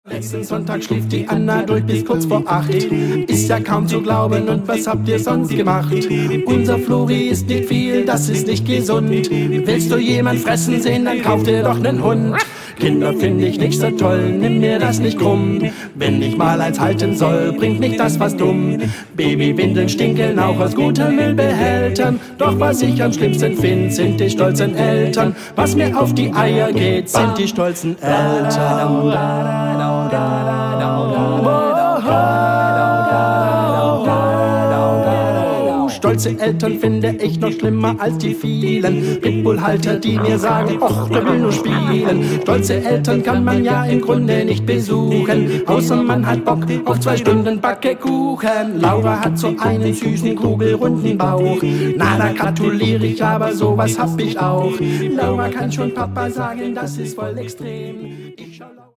Die Studio-CD